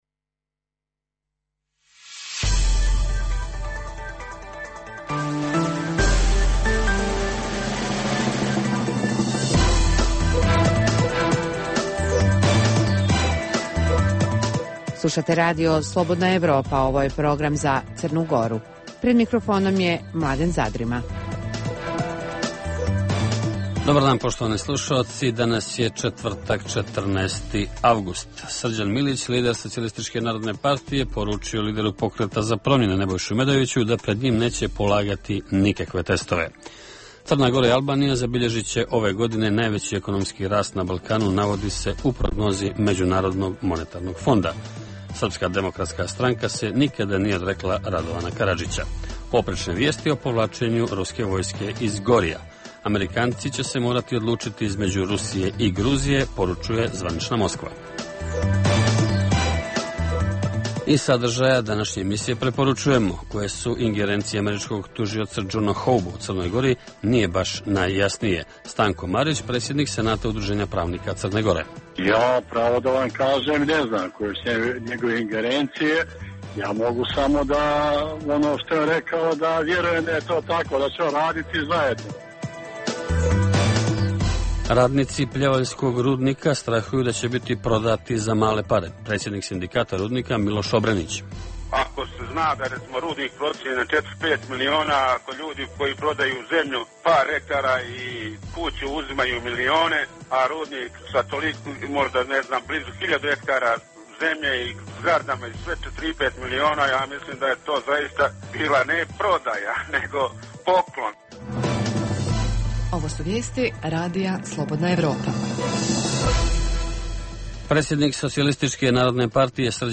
Što se očekuje od Hoba i koje su njegove ingerencije? Redovan sadržaj emisije četvrtkom je intervju u okviru serijala "Crna Gora i region".